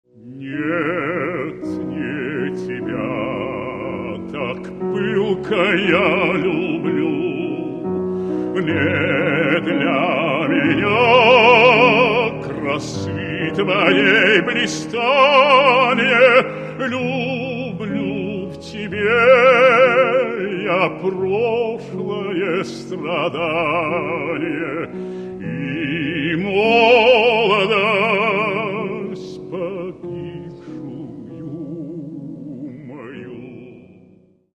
Каталог -> Классическая -> Опера и вокал